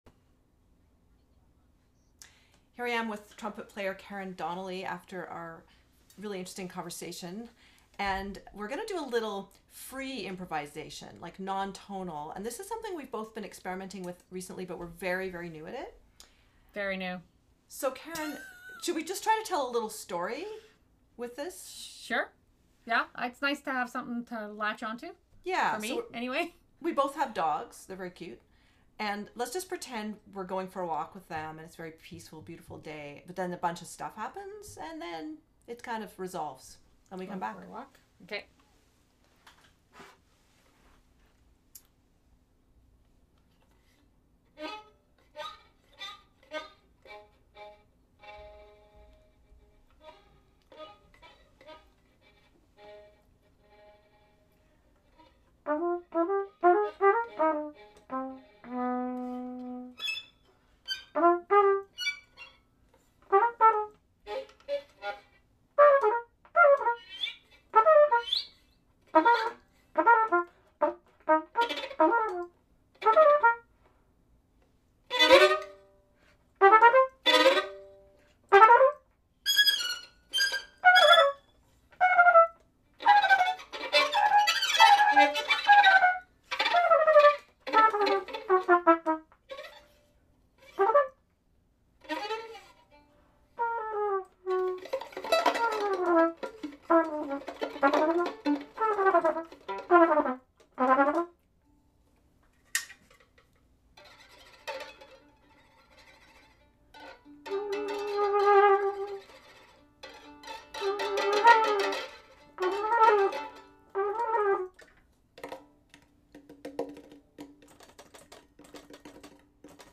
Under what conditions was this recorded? Free improvisation was very new to both of us when we recorded this, but we had fun with this little back-and-forth across Zoom.